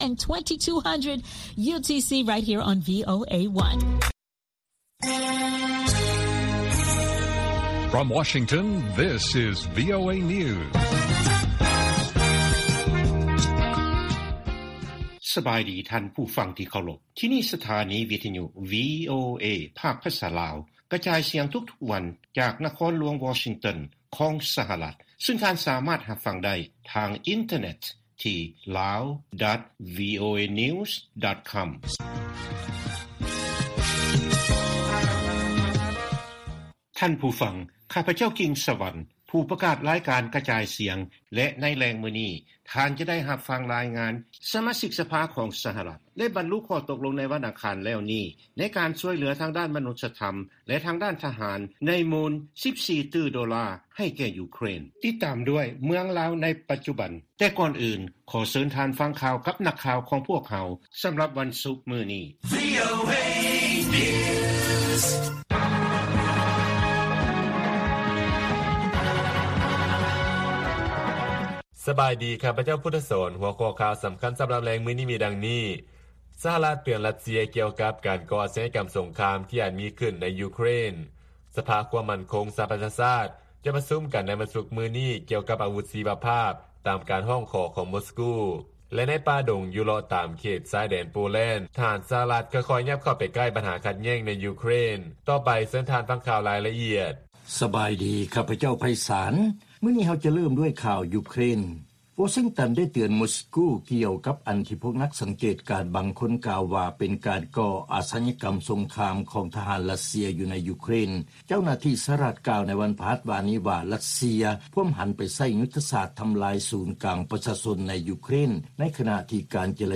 ລາຍການກະຈາຍສຽງຂອງວີໂອເອ ລາວ: ສະຫະລັດ ເຕືອນຣັດເຊຍ ກ່ຽວກັບ ການກໍ່ອາຊະຍາກຳສົງຄາມ ‘ທີ່ອາດມີຂຶ້ນ’ ໃນຢູເຄຣນ.